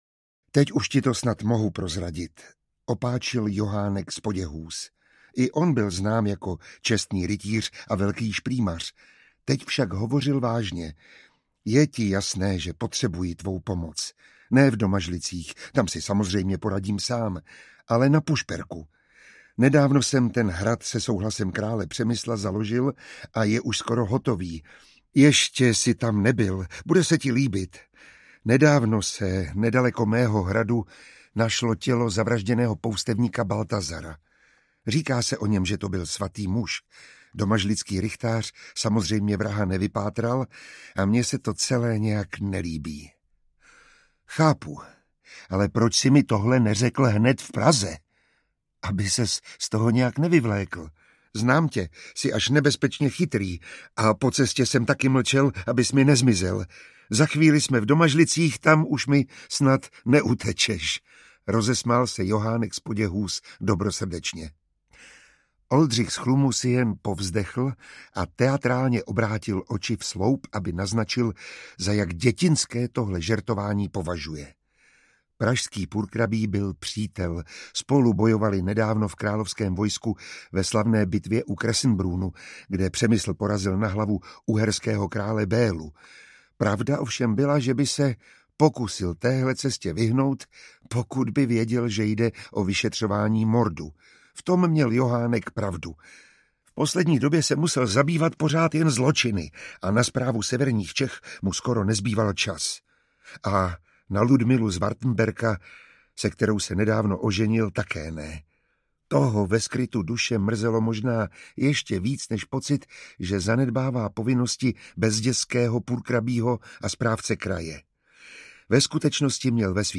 Smrt šumavského poustevníka audiokniha
Ukázka z knihy
Čte Miroslav Táborský.
Vyrobilo studio Soundguru.